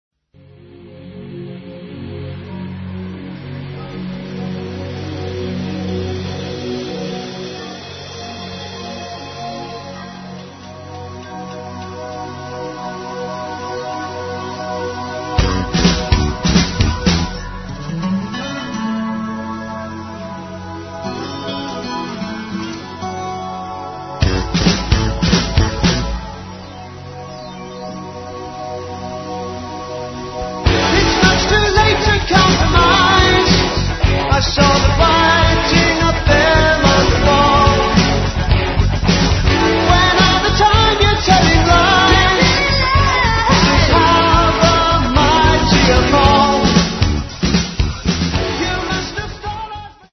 Diploma Studio, Malden, Essex 1990.